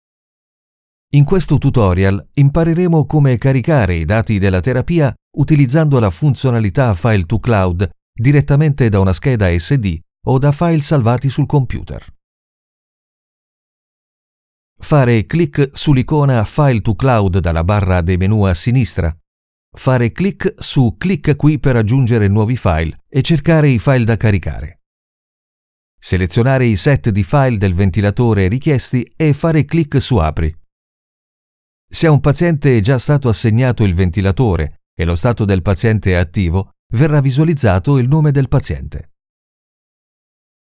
Naturelle, Polyvalente, Fiable, Mature, Douce
Vidéo explicative